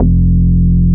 P MOOG F2P.wav